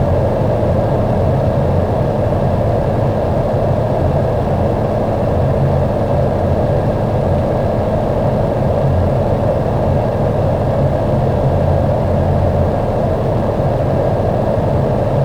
It provides calming, non-looping sounds to help lull you to sleep.
• Features full-spectrum sound
• Non-looping white noise
Light Fan
The Real Fan White Noise Sound Machine fills your room with peaceful sounds and calming hums of moving air using its built-in fan.
LightFan.mp3